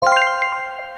Correct Answer.mp3